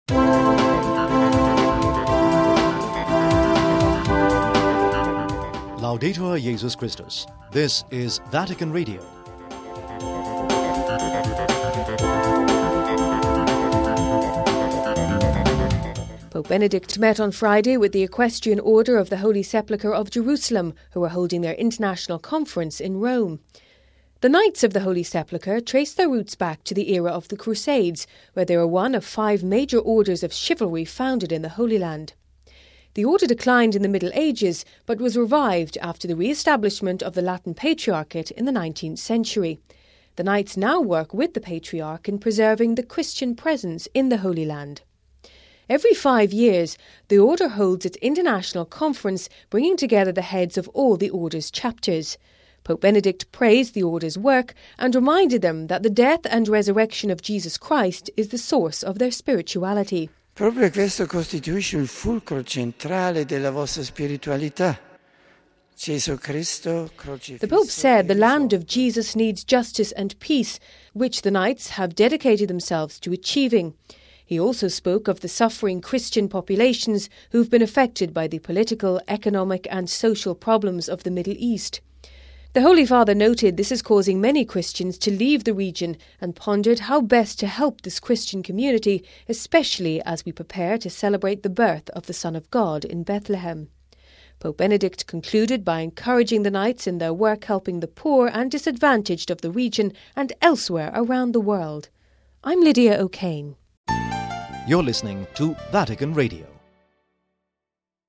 (05 Dec 08 - RV) Pope Benedict met on Friday with the Equestrian Order of the Holy Sepulchre of Jerusalem, who are holding their International Conference in Rome. We have this report...